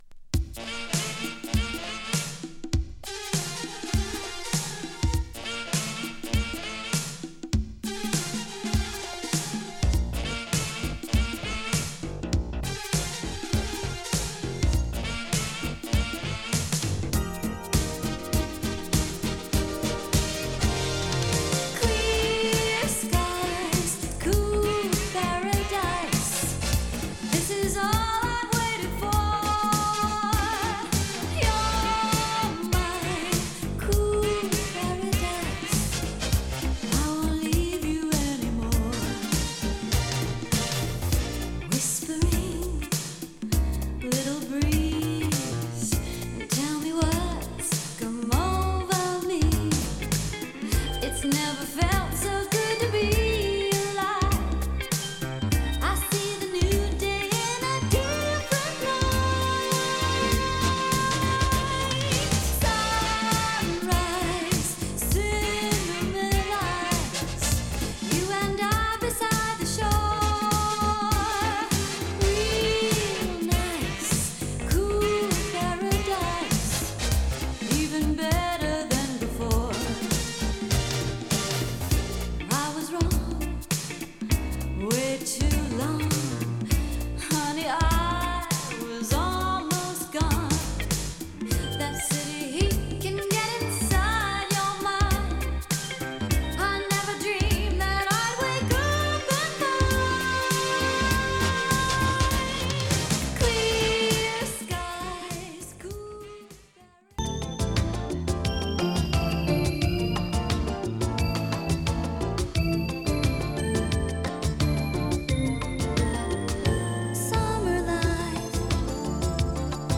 パーカッションの効いたCalypso入ったラテン・チューン